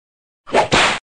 GBci3fpY43h_Coup-de-fouet-bruitage-.mp3